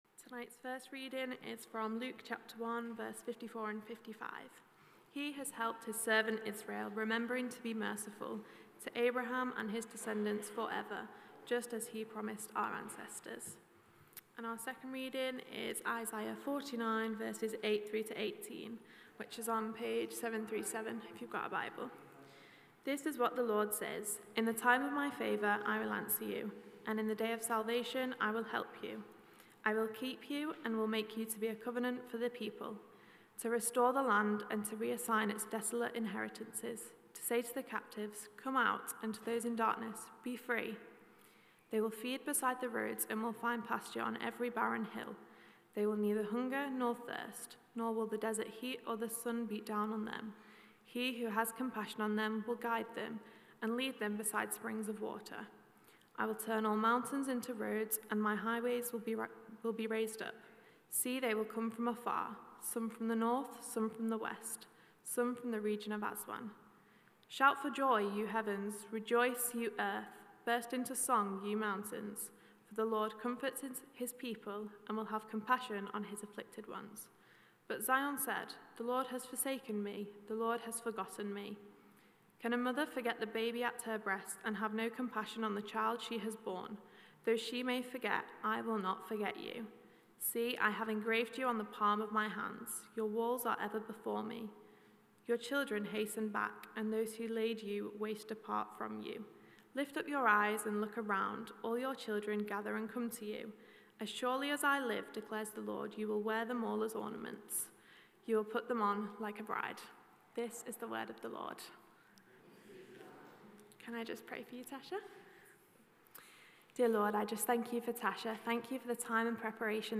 This week is the final week of our sermon series, The Great ADVENTure, in the run up to advent! We’ve been looking at Mary’s song, The Magnificat, and reflecting on how we prepare to receive Jesus.